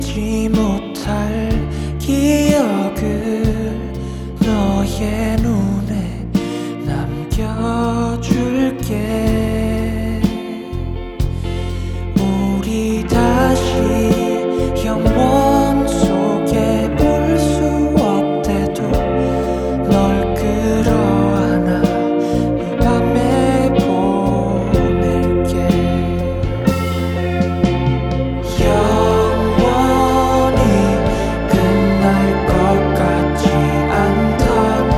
Folk Pop K-Pop
Жанр: Поп музыка / Фолк